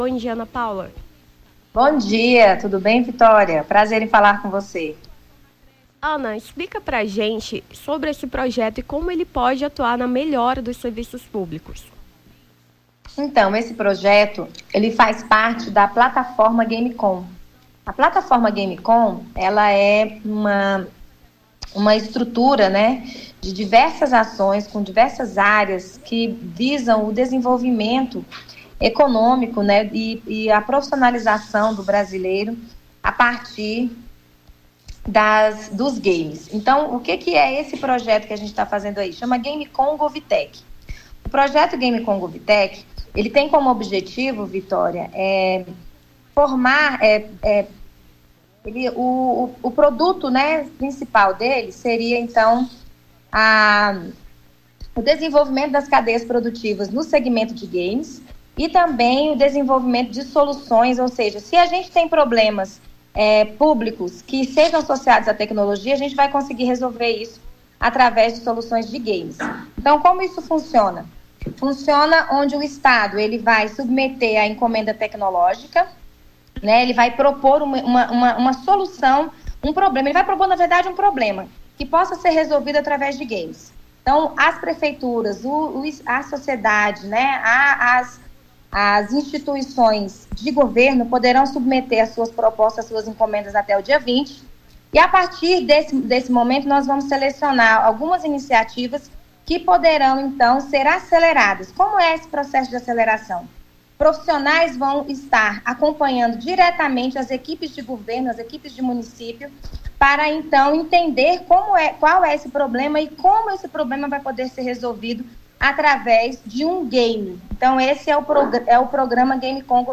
Nome do Artista - CENSURA - ENTREVISTA (GAMES SOLUÇÃO MUNICIPIOS) 02-10-23.mp3